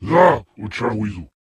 Jiralhanae voice clip from Halo: Reach.
Category:Covenant speech